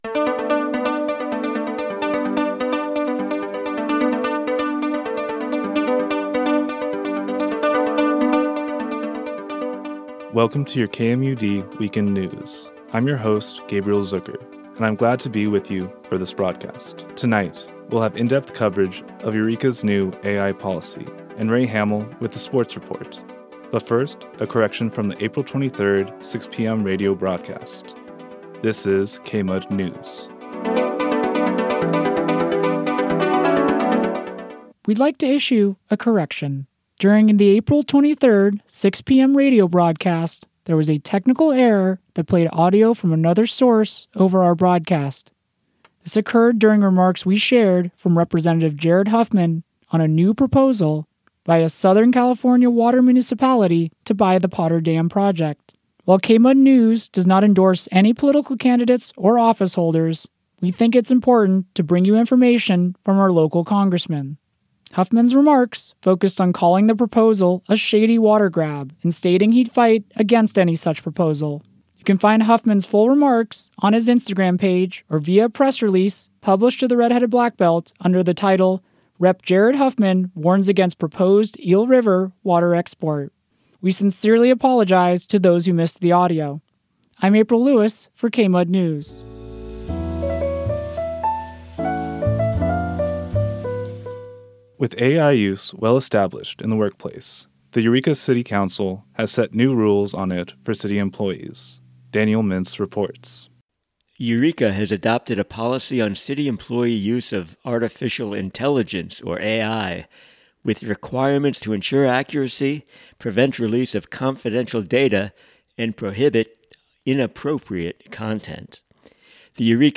Local News 04 24 26